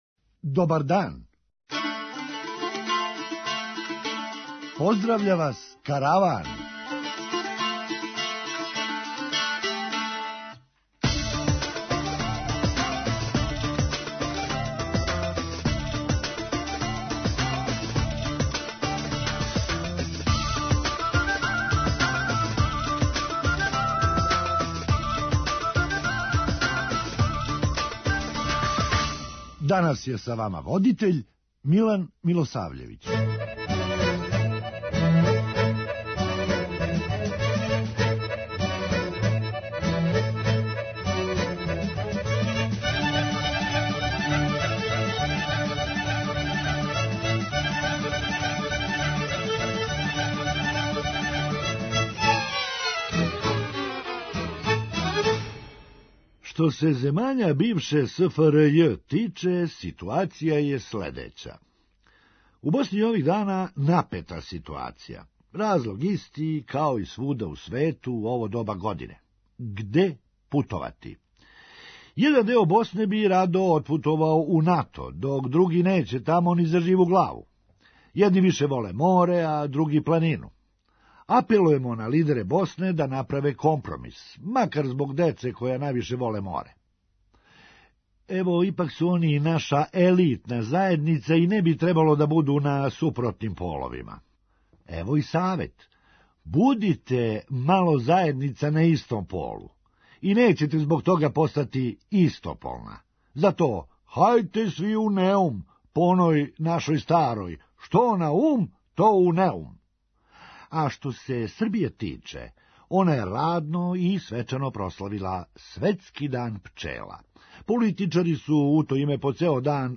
Хумористичка емисија
Толико је безбедан да и комарци не страхују већ се само множе и множе! преузми : 9.26 MB Караван Autor: Забавна редакција Радио Бeограда 1 Караван се креће ка својој дестинацији већ више од 50 година, увек добро натоварен актуелним хумором и изворним народним песмама.